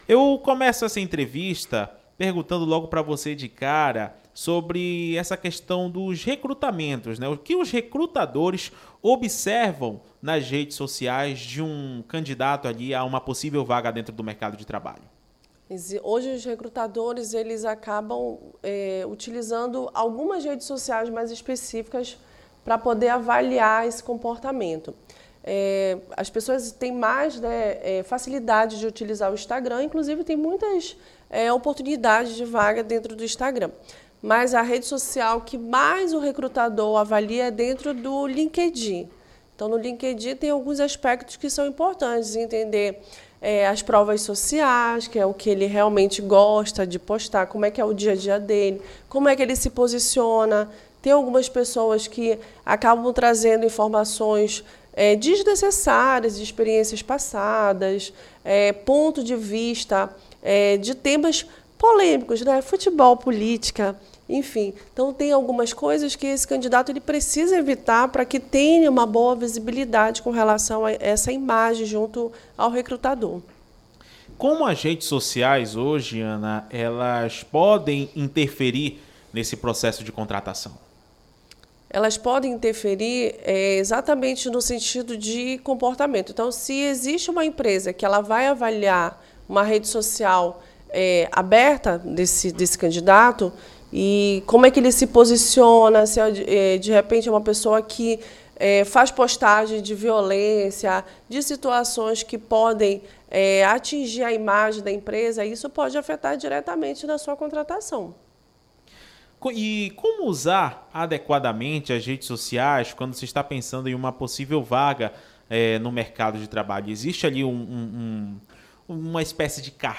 3---ENTREVISTA-REDE-SOCIAS---03.02.mp3